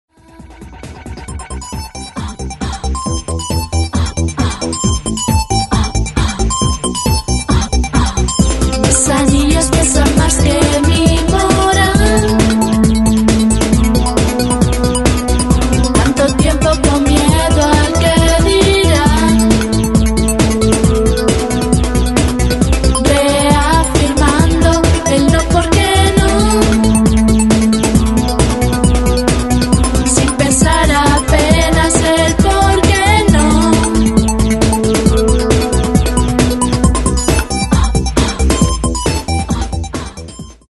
mezclando música de baile, ritmos tribales y sonidos